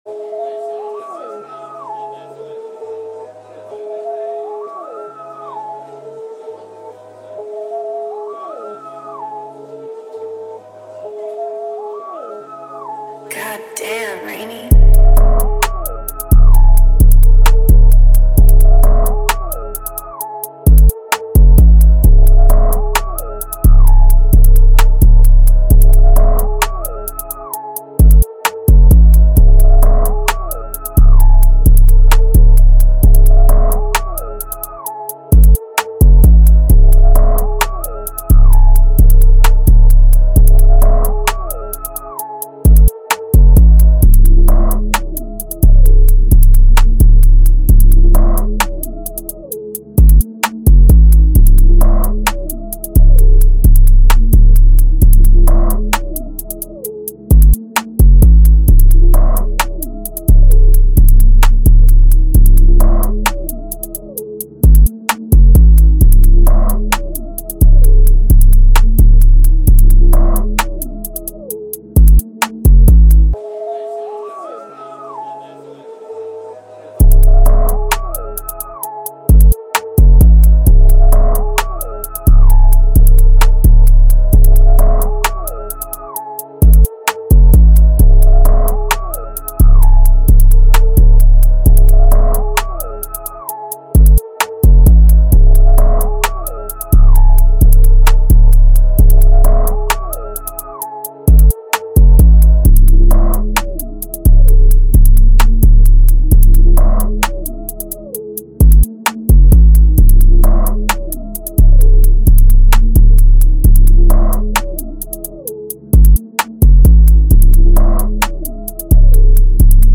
131BPM (RAP/HIPHOP/TRAP) CO